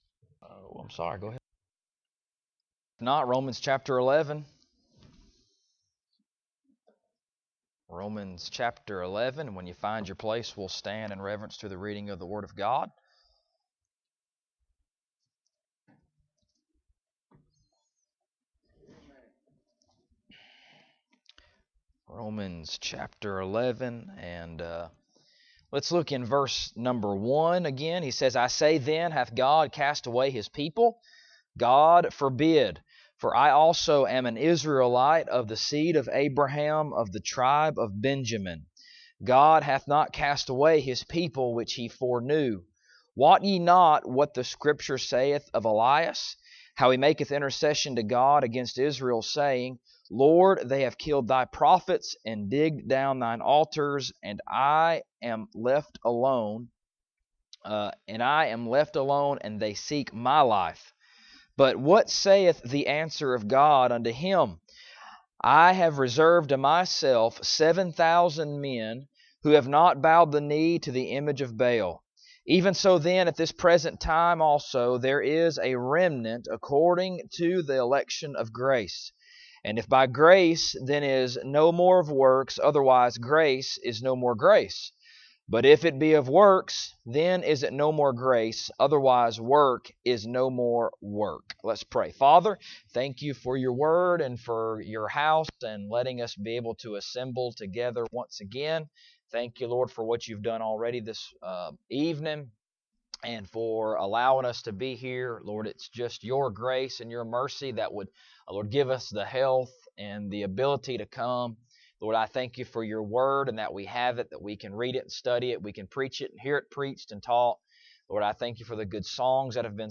Romans Passage: Romans 11:1-6 Service Type: Sunday Evening « Hath God Cast Away His People?